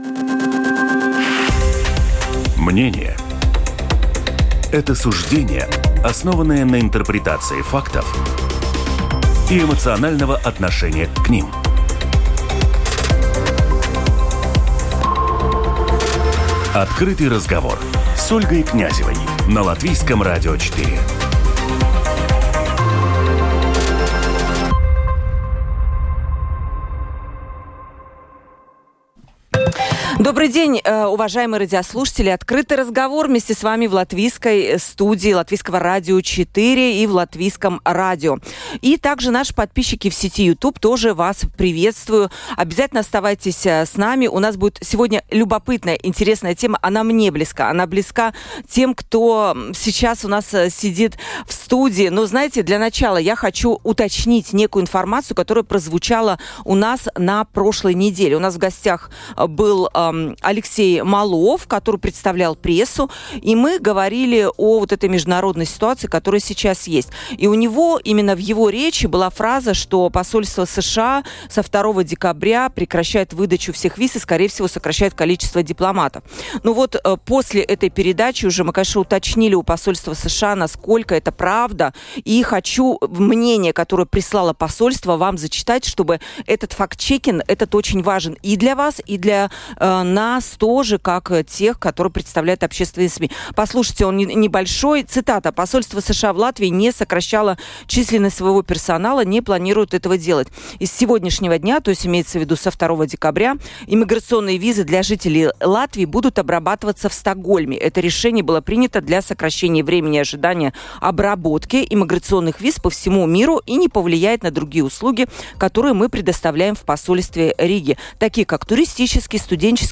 Обсудим эту тему с нашими экспертами.